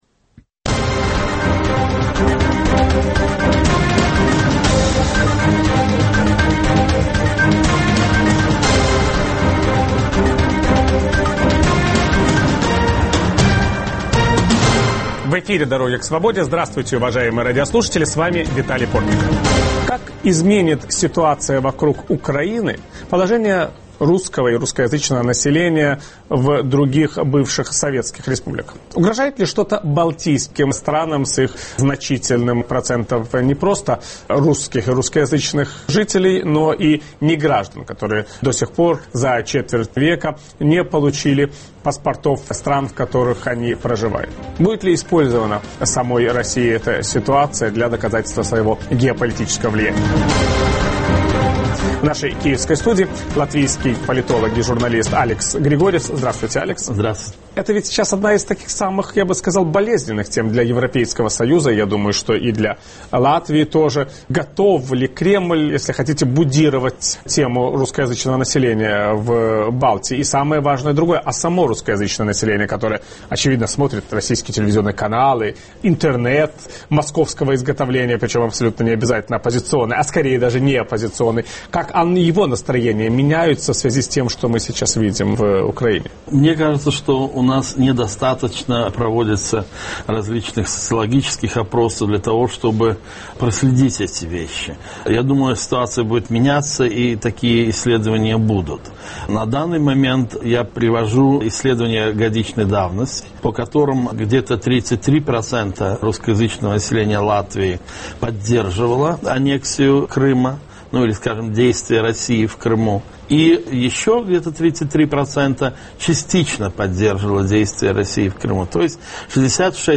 Гость программы "Дороги к свободе" - беседует с латвийский политолог и журналист, бывший депутат парламента Латвии Алекс Григорьевс.